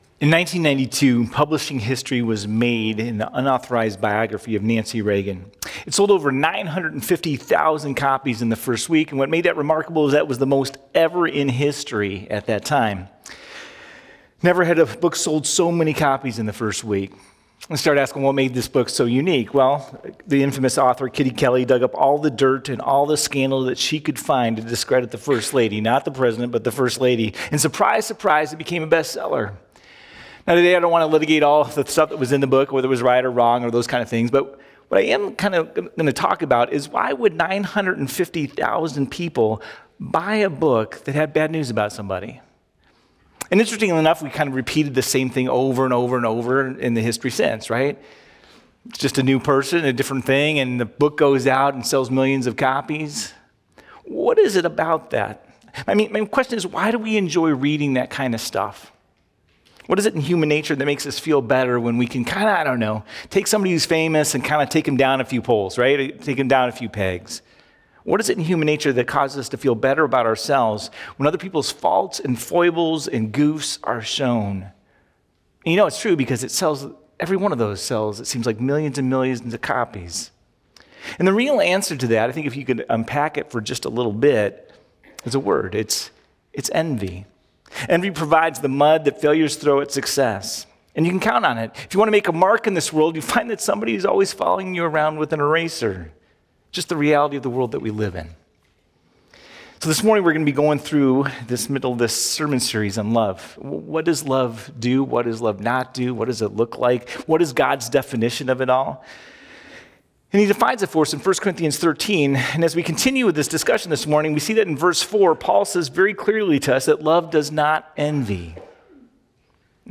0302-Sermon.mp3